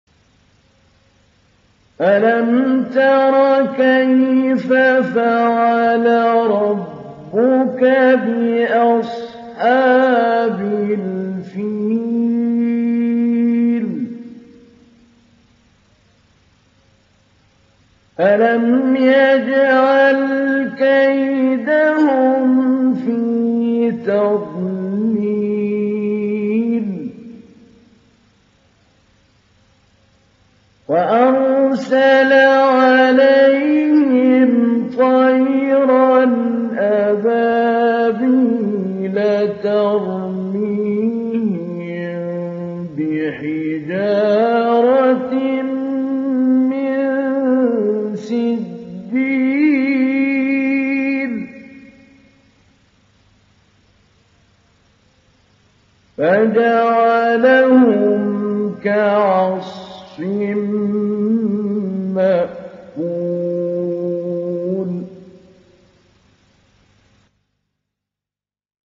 دانلود سوره الفيل محمود علي البنا مجود